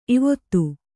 ♪ ivottu